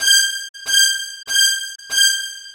Strings 02.wav